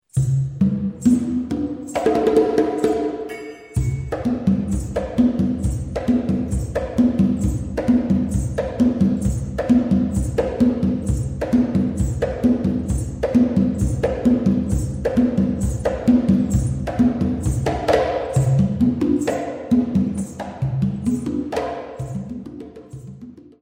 Both CDs contain a variety of all Percussion Music